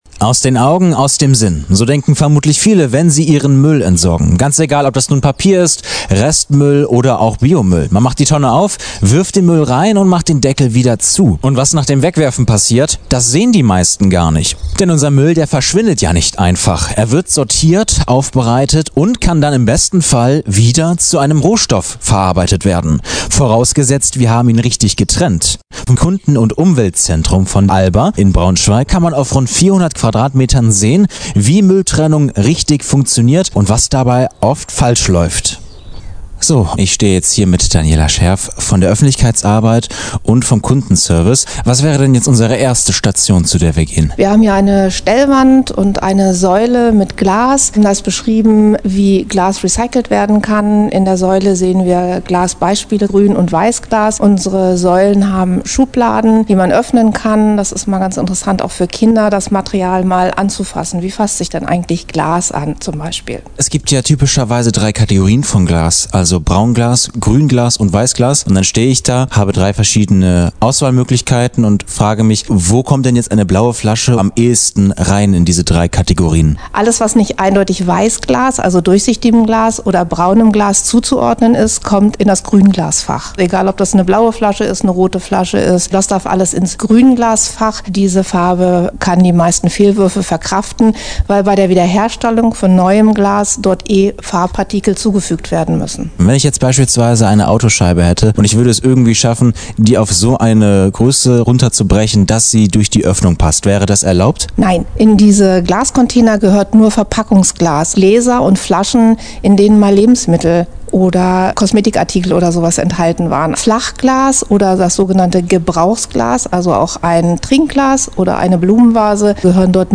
Mülltrennung ja – aber richtig: Hausbesuch im Kunden- und Umweltzentrum von ALBA Braunschweig - Okerwelle 104.6